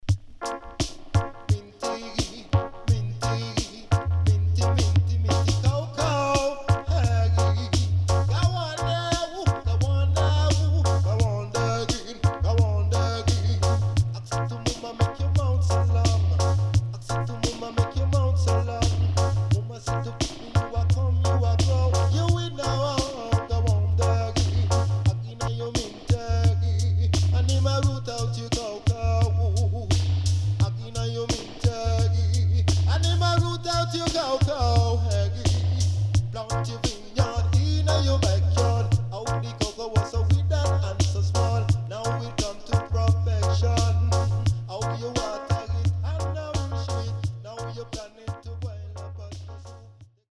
HOME > REISSUE USED [DANCEHALL]
SIDE A:少しノイズ入りますが良好です。